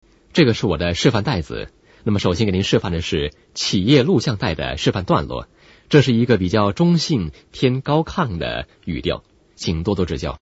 Sprecher chinesisch / mandarin für Werbung, internet, podcast, e-learning, Dokumentationen uva.
Kein Dialekt
chinese voice over talent